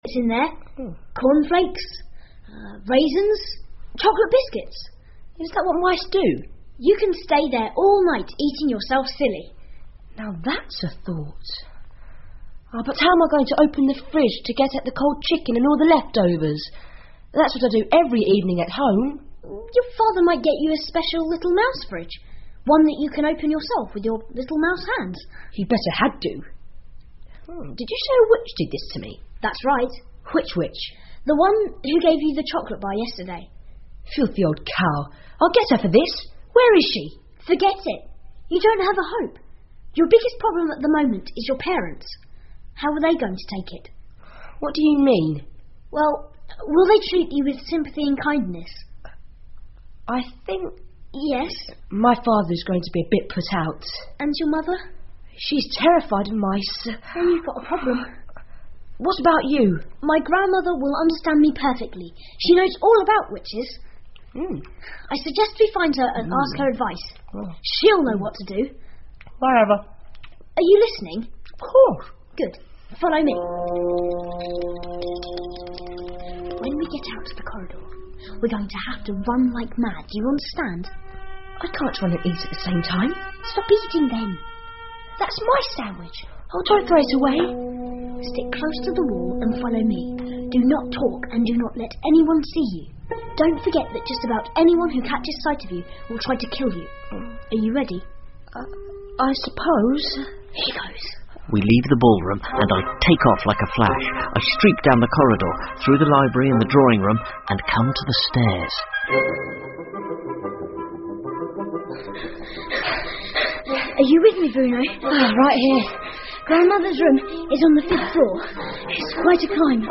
女巫 The Witches 儿童英文广播剧 13 听力文件下载—在线英语听力室